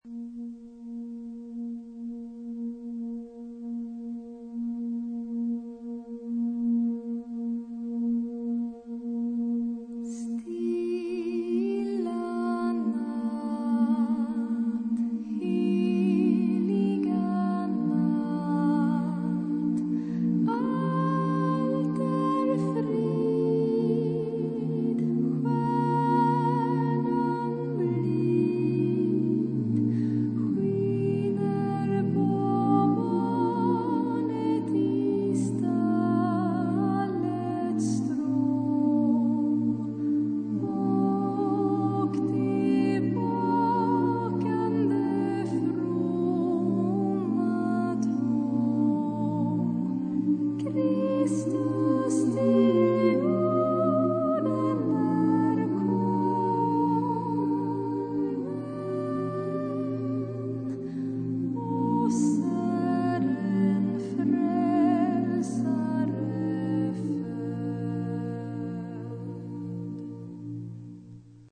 Mood of the piece: jazzy ; slow
Type of Choir: SSAATTBB  (8 mixed voices )
Soloist(s): Soprano (1) / Tenor (1)  (2 soloist(s))
Tonality: B flat major